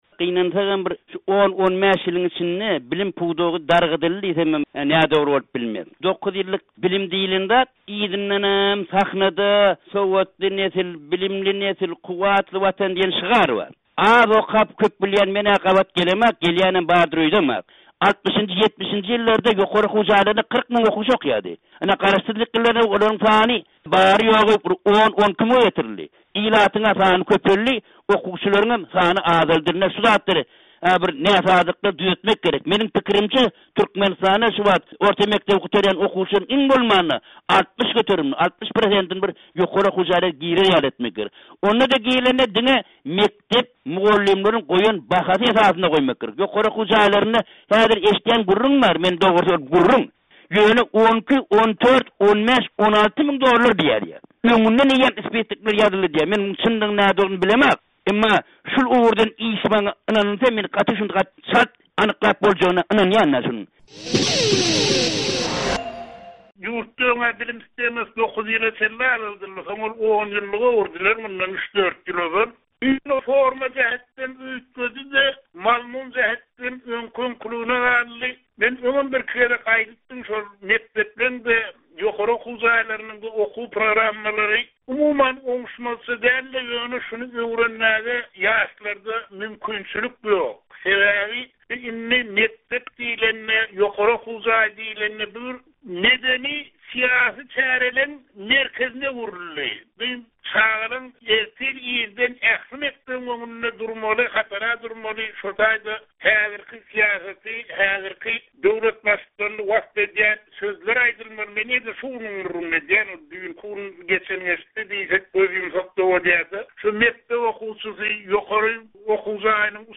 Azatlyk radiosy bu tema boýunça dürli ýyllarda guran söhbetdeşliklerden käbir bölekleri okyjylaryň we diňleýjileriň dykgatyna ýetirmek kararyna geldi.